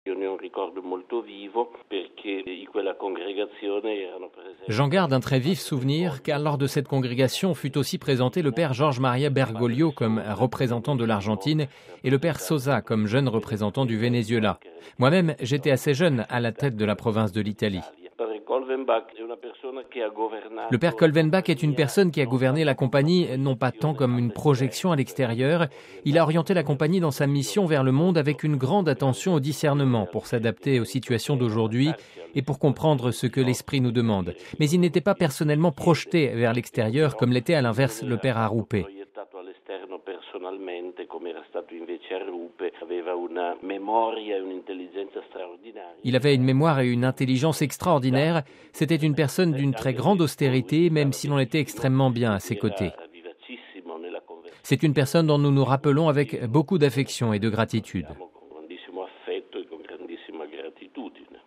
Le père Federico Lombardi, provincial des Jésuites d’Italie dans les années 1980, évoque son souvenir de la Congrégation générale de 1983, à laquelle il avait participé, ainsi que le futur Pape François et le père général actuel, Arturo Sosa…